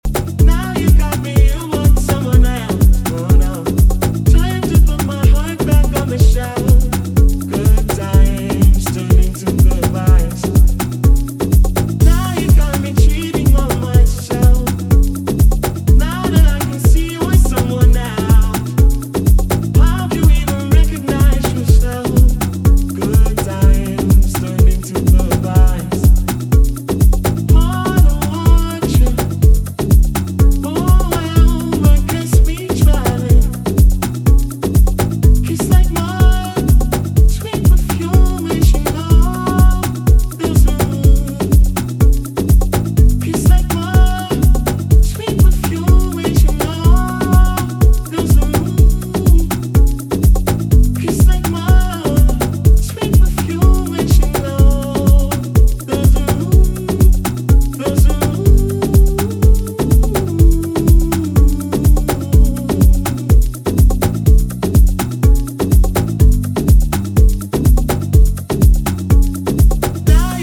two tracks that will work dance floors in equal measure